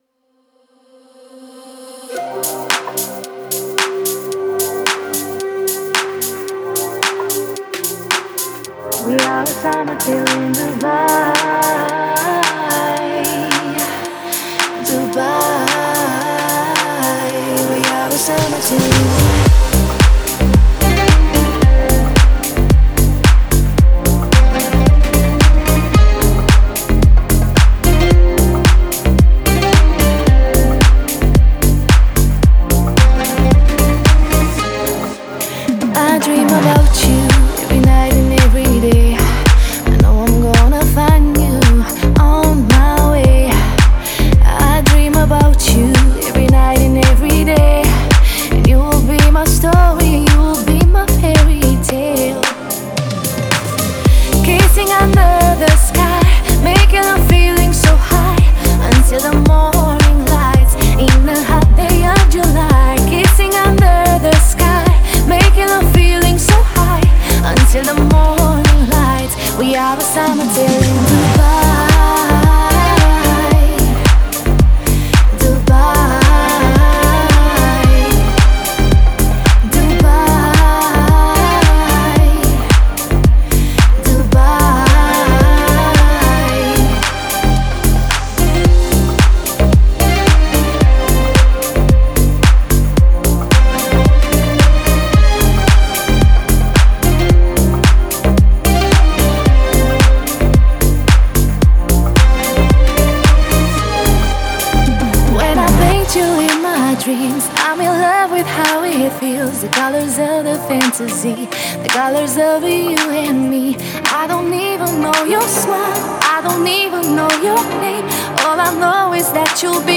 это завораживающий трек в жанре прогрессивного house